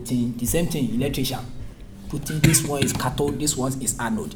S1 = Bruneian female S2 = Bruneian male S3 = Nigerian male Context : S3 is talking about how the the sort of things students in ITB (Institut Teknologi Brunei, the Brunei Institute of Technology) learn in their classes.